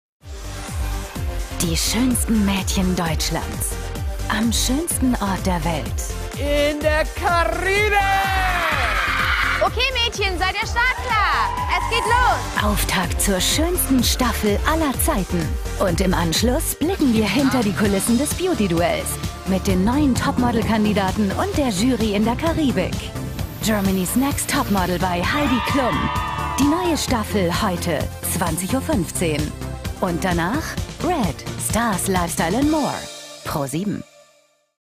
TV-TRAILER / Station Voice